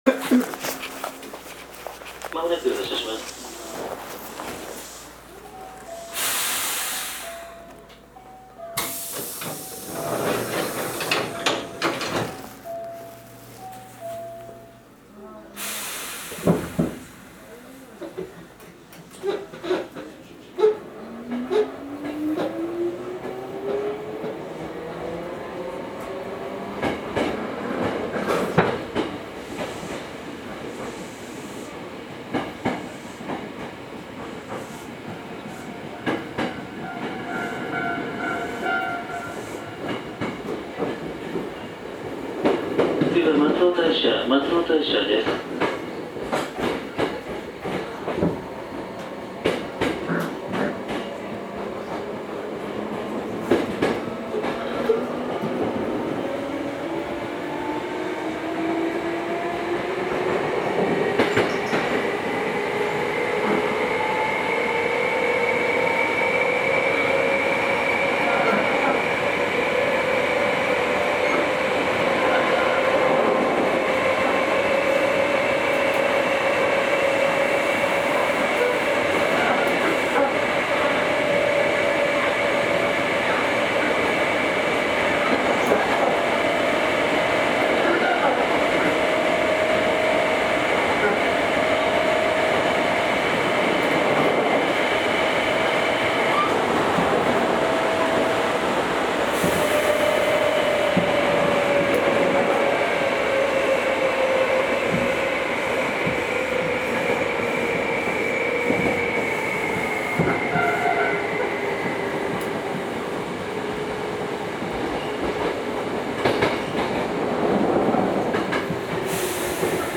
走行音
録音区間：上桂～松尾大社(お持ち帰り)